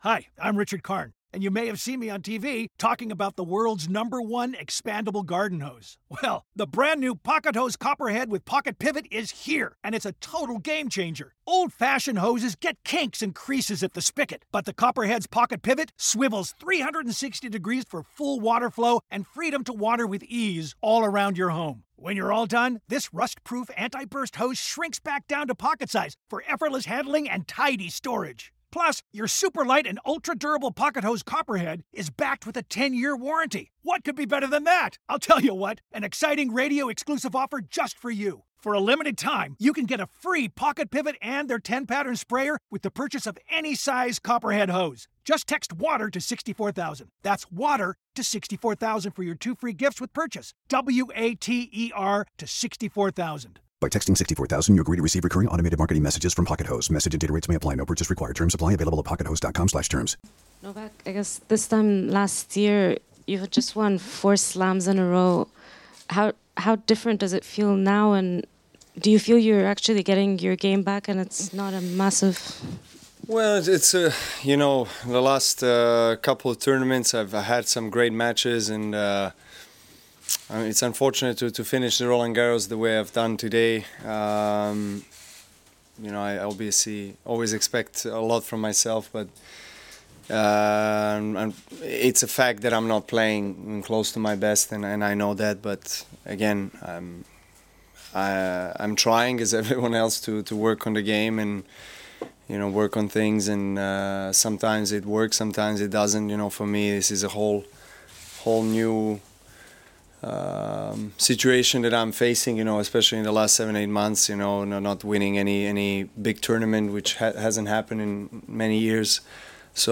Here's Novak Djokovic on his loss to Dominic Thiem in the Quarter Final at Roland-Garros